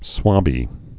(swŏbē)